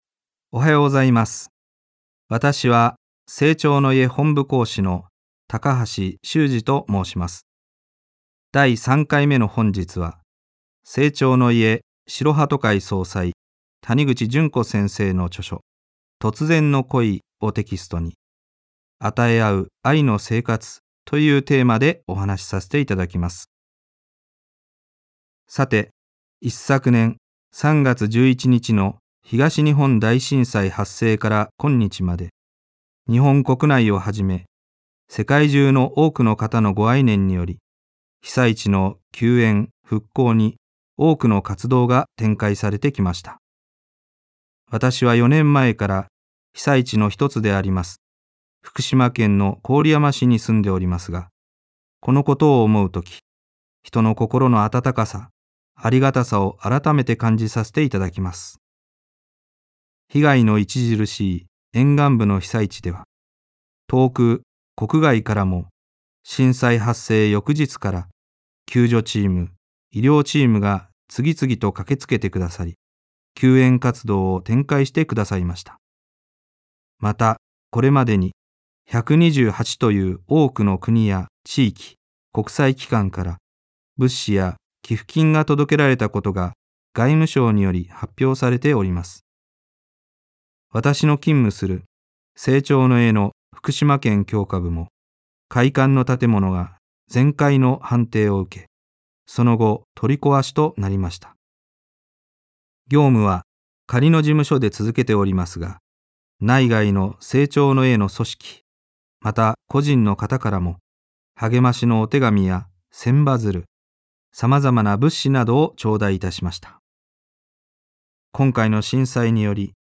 生長の家がお届けするラジオ番組。